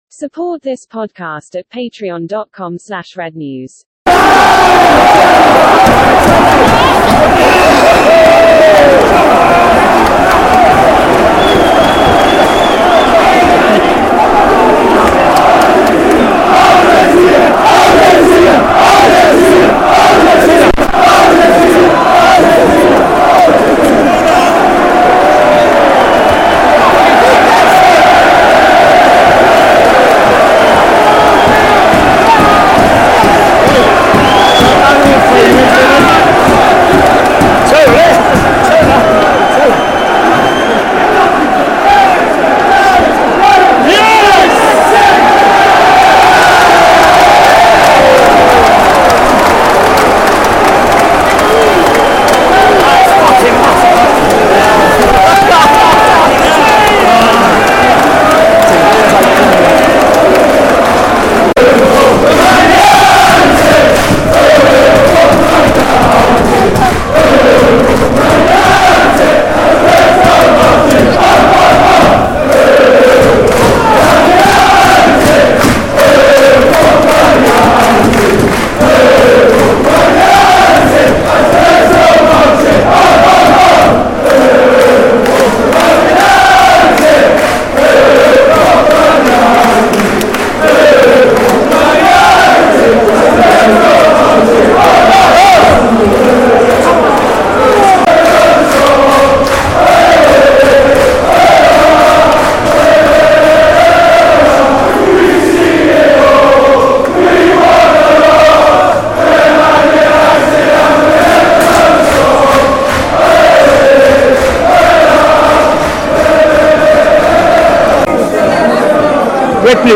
At the Utd end at Fulham; in the pub after, then the dungeon, and one final drunken crisp chat in San Sebastian.